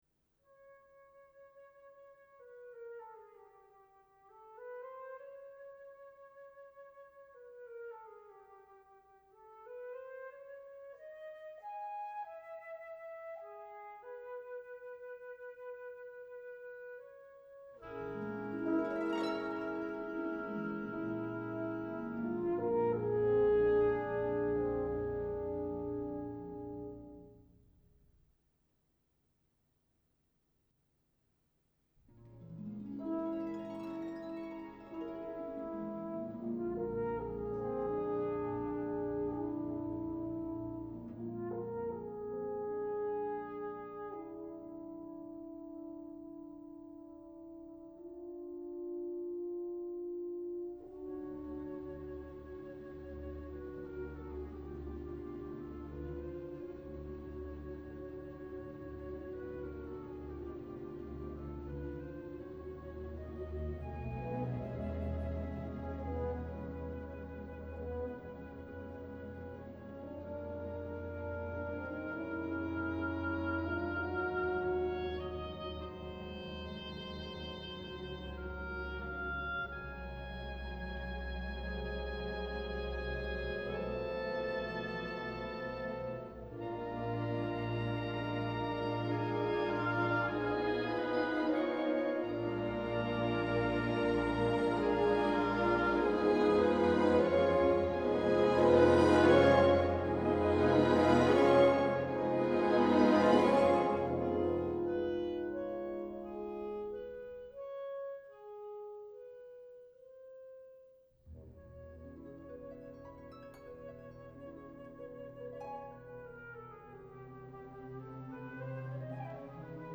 Professionally Edited Recordings